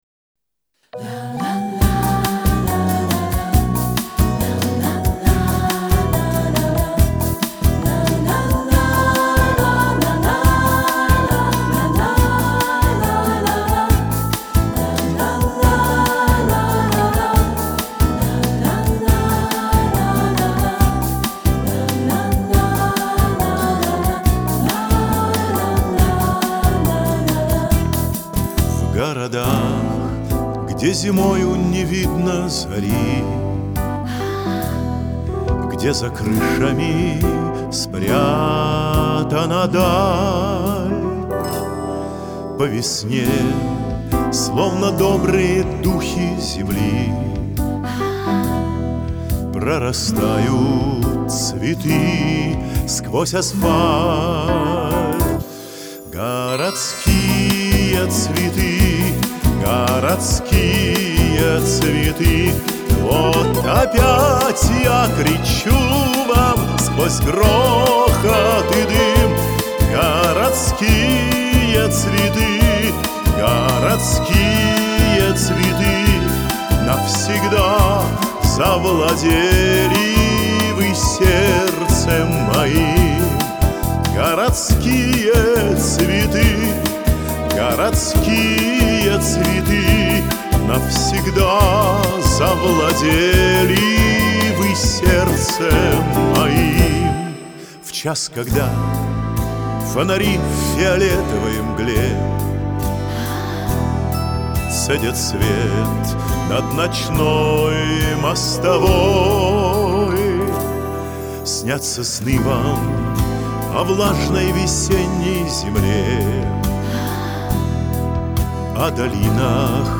Жанр: Retro